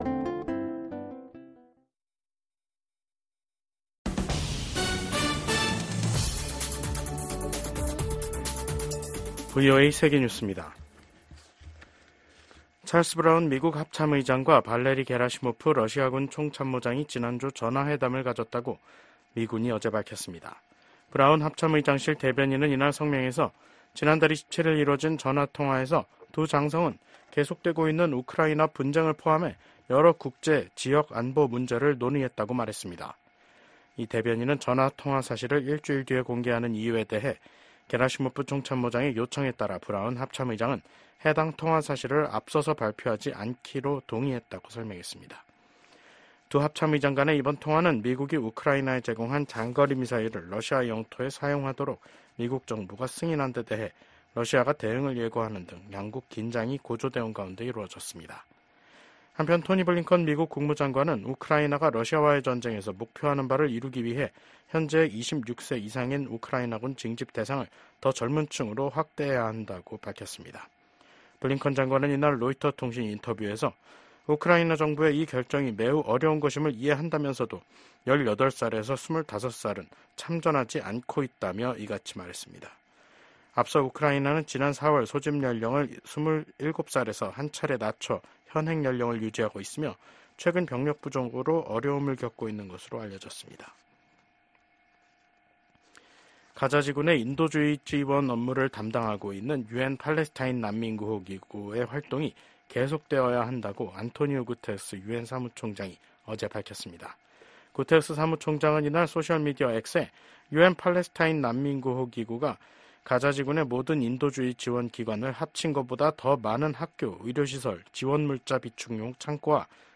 VOA 한국어 간판 뉴스 프로그램 '뉴스 투데이', 2024년 12월 5일 2부 방송입니다. 한국 6개 야당이 비상계엄 선포와 관련해 발의한 윤석열 대통령 탄핵소추안이 7일 국회에서 표결에 부쳐질 예정입니다. 미국 국무장관이 한국은 전 세계에서 가장 모범적인 민주주의 국가 중 하나라면서 윤석열 한국 대통령의 비상계엄 해제 결정을 환영했습니다.